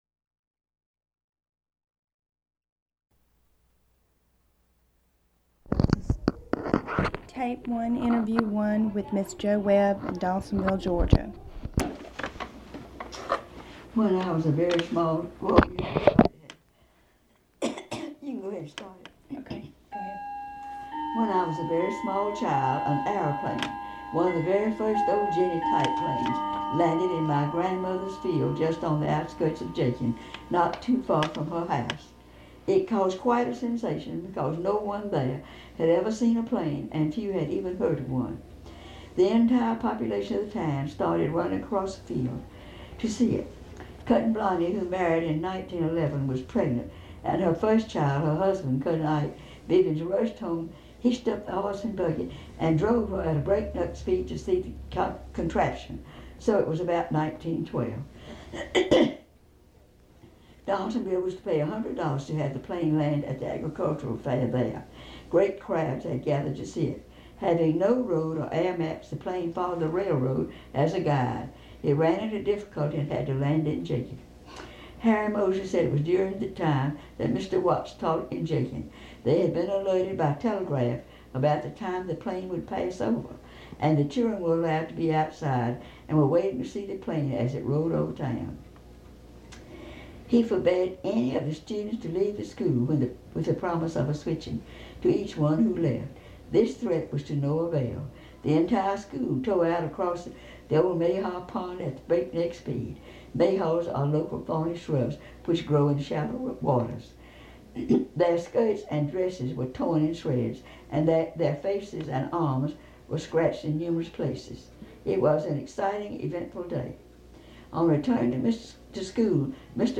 Recorded in Donalsonville, Georgia.